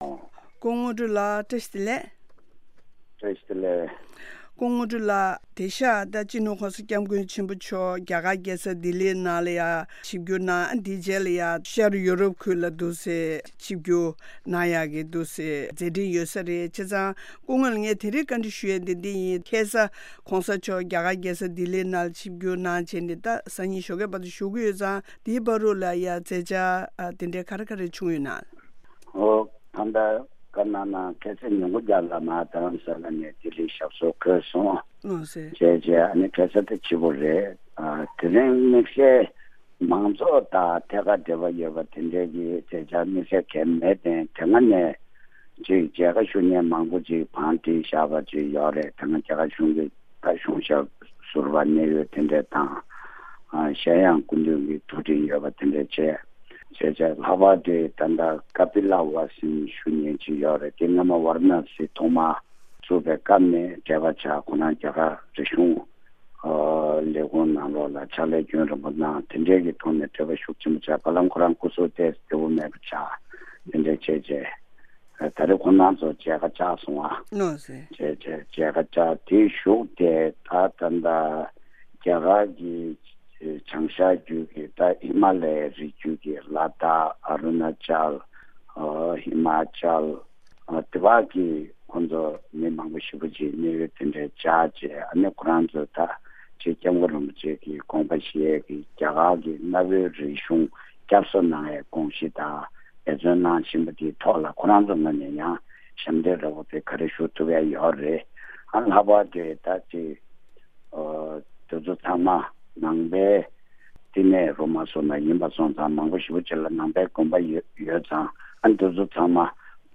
བཅར་འདྲི་